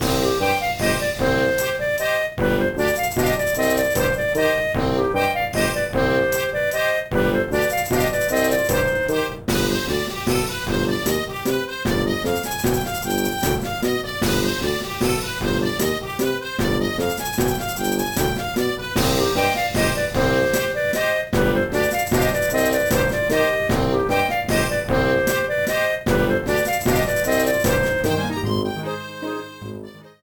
Clipped to 30 seconds and added fade-out.